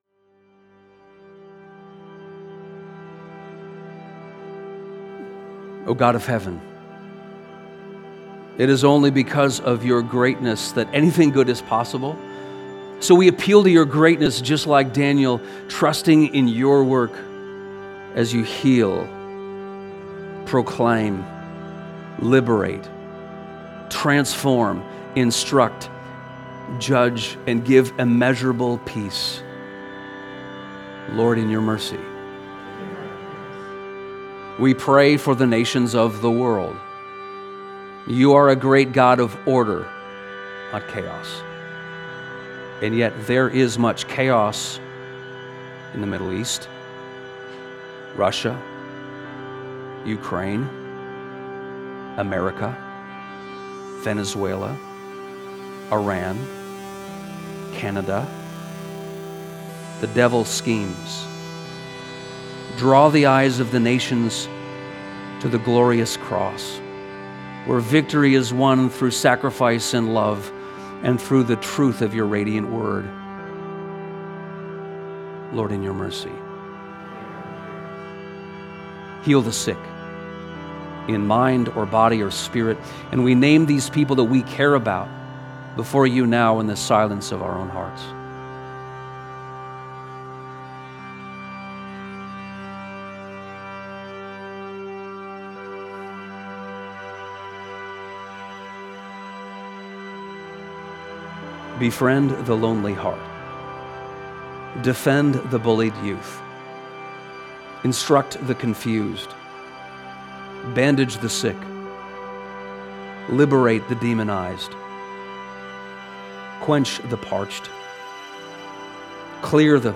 during the worship service at Westminster on January 18, 2026.